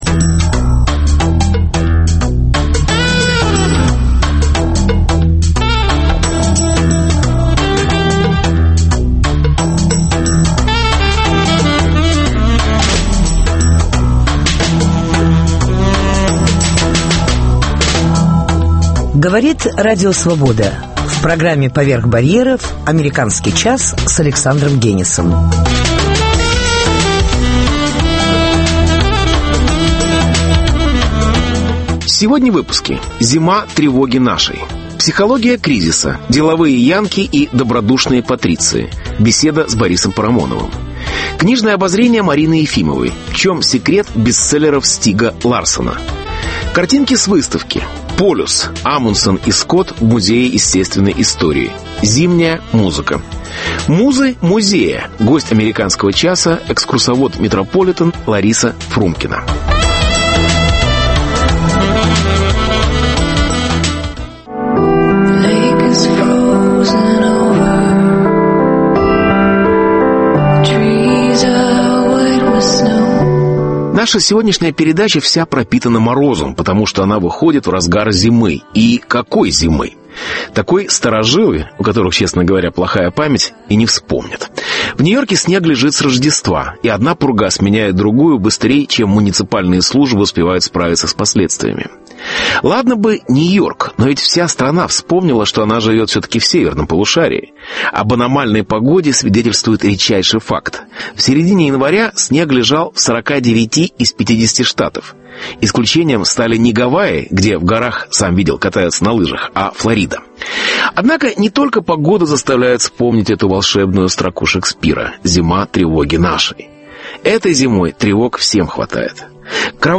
Психология кризиса: деловые янки и добродушные патриции. Беседа с Борисом Парамоновым.